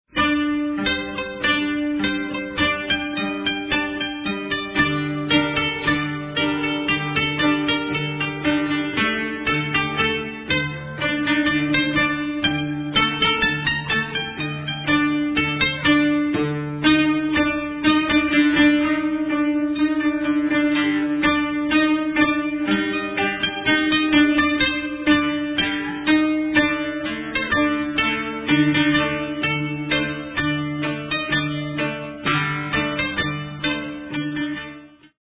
traditional Japanese music
Recorded in Japan